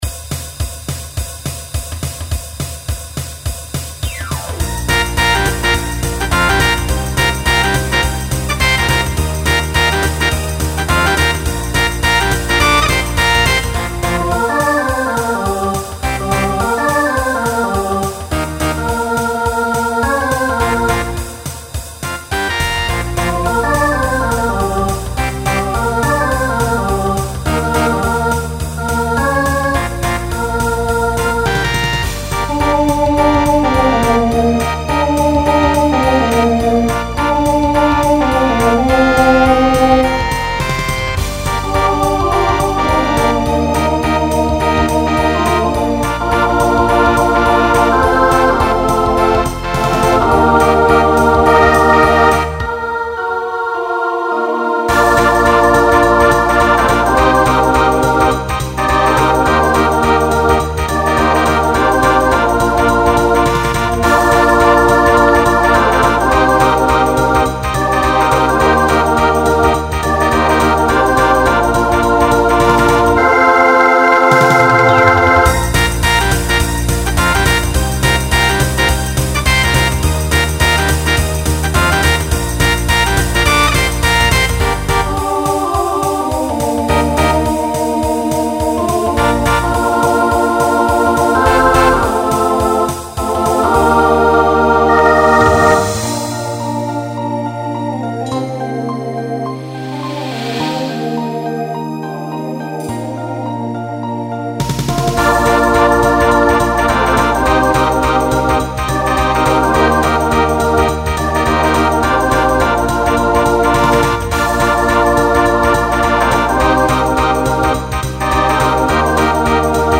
Genre Rock Instrumental combo
Transition Voicing SATB